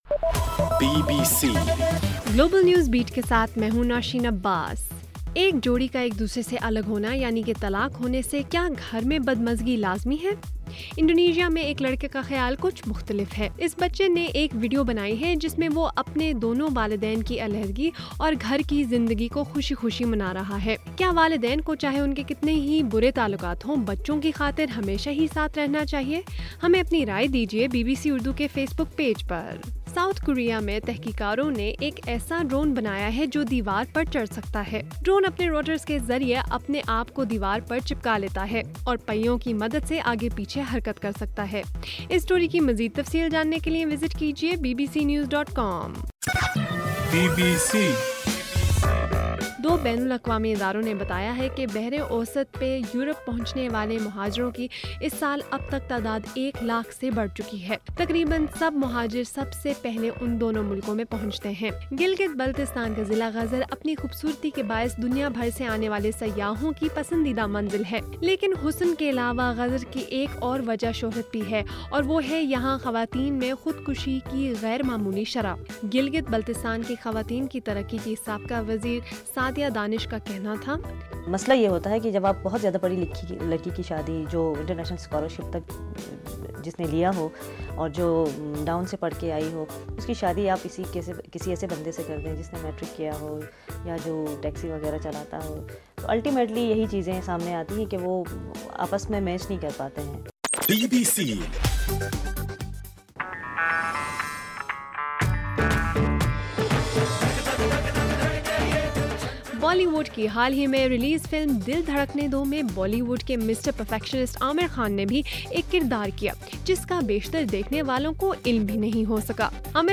جون 9: رات 8 بجے کا گلوبل نیوز بیٹ بُلیٹن